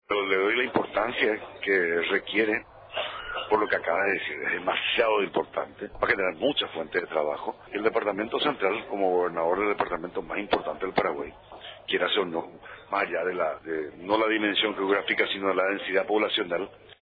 20-HUGO-JAVER-GONZALE-Z–-GOBERNADOR-DE-CENTRAL.mp3